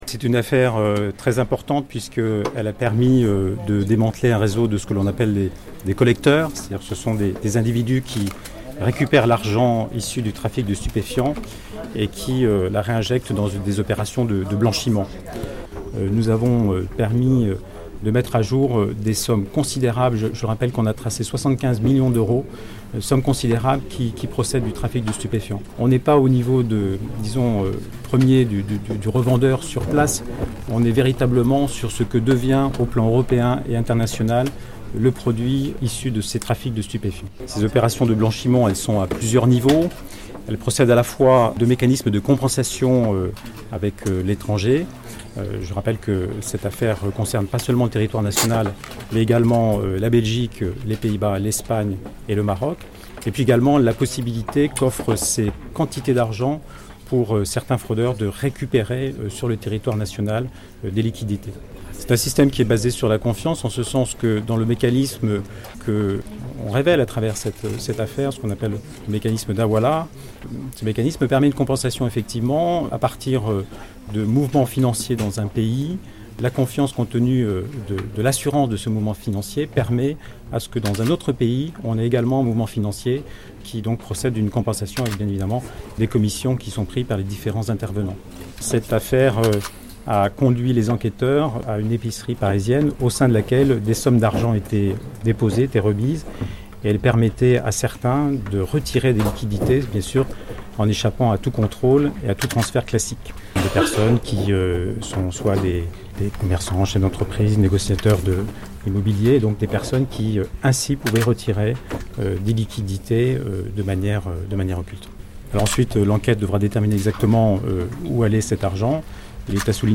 Réunis à l’occasion d’une conférence de presse ce mardi 29 novembre à Marseille (Bouches-du-Rhône), des magistrats et policiers ayant participé à l’enquête parlent d’une «affaire hors normes».
Il s’agit de fragmenter la chaîne par laquelle transite l’argent, à l’aide de collecteurs et de convoyeurs, afin de permettre aux trafiquants d’utiliser les revenus issus de la vente de la drogue sans se faire repérer. son_copie_petit-66.jpgLe procureur de la République de Marseille Xavier Tarabeux revient sur ce dispositif bien huilé des collecteurs du réseau